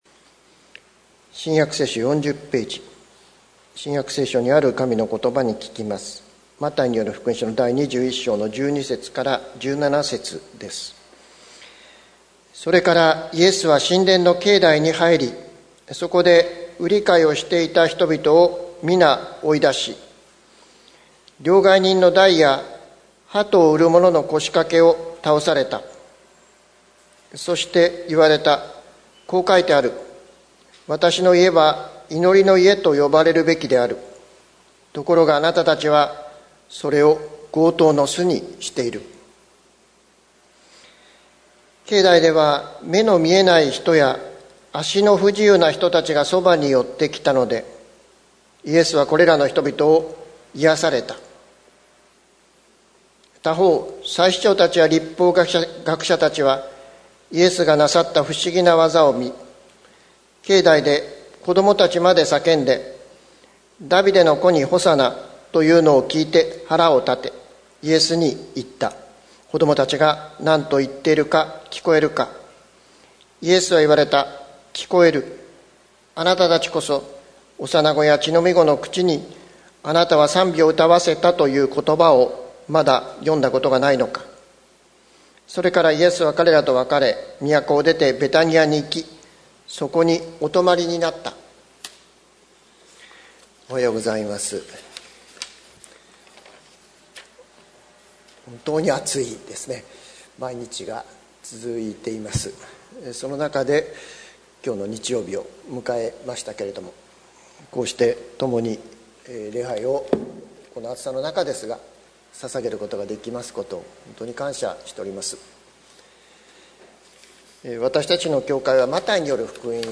2020年08月16日朝の礼拝「祈りの家に生きる」関キリスト教会